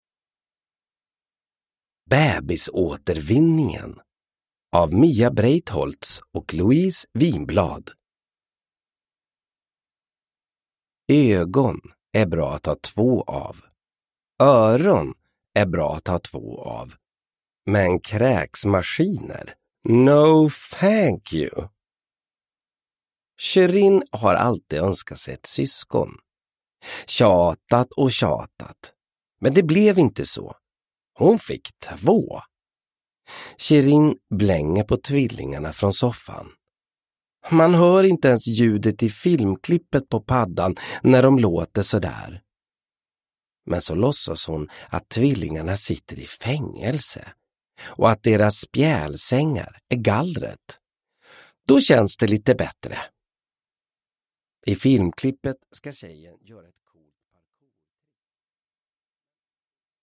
Bebisåtervinningen – Ljudbok
Uppläsare: Olof Wretling